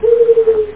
Amiga 8-bit Sampled Voice
SmallPigeon.mp3